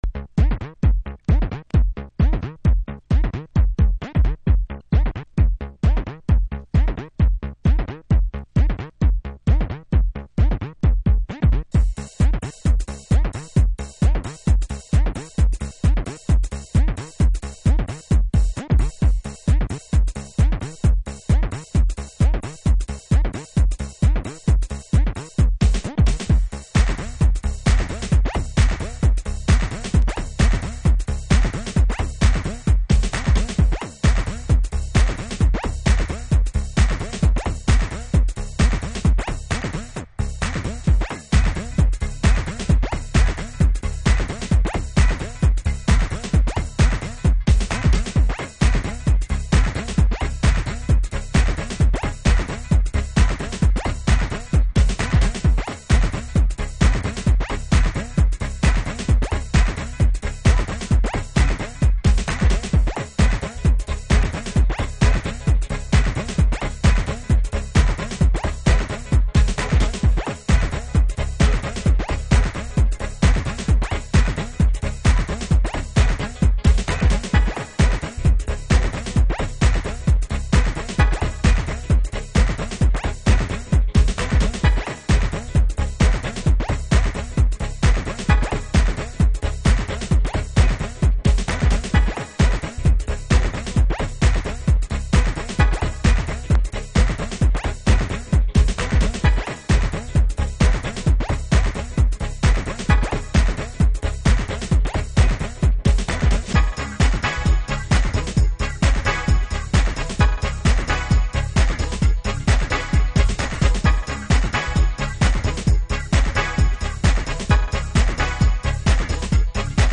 Detroit House / Techno